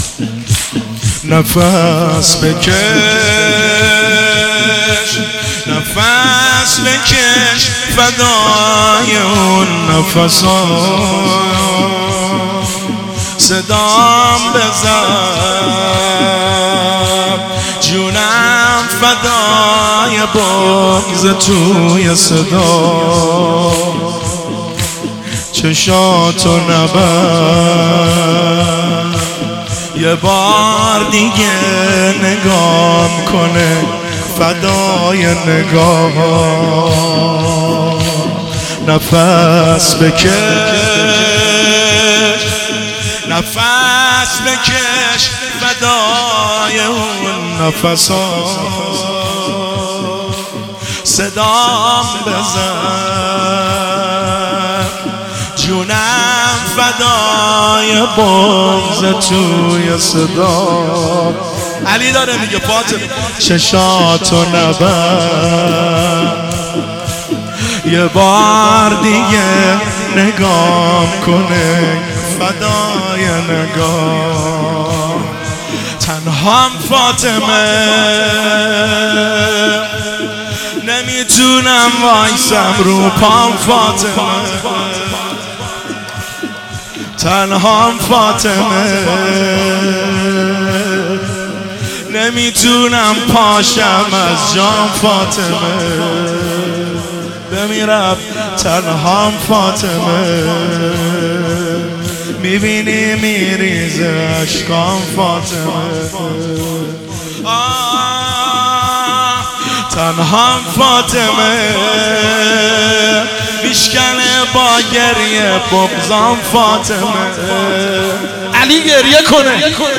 هیئت بین الحرمین طهران
نفس-بکش-زمینه.mp3